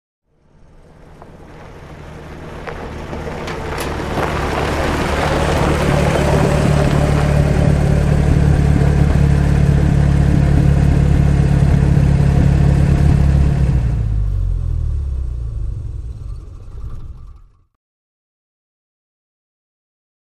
Aston Martin; In / Stop / Off; Slow Approach On Dirt Or Gravel, Fine Debris Bouncing Against Chassis, Irregular Throbbing In Engine, Off With Slow Rev Down And Squeak. Medium To Close Perspective. Sports